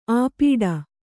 ♪ āpīḍa